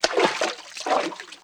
MISC Water, Splash 03.wav